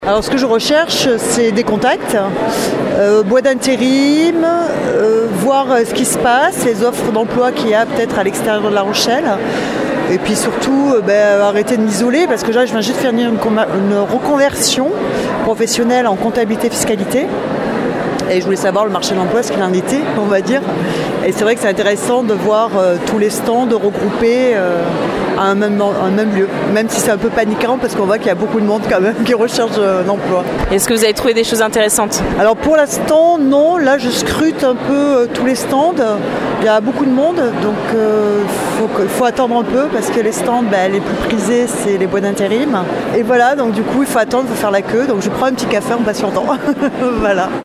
Forte affluence hier après-midi au forum Destination emploi.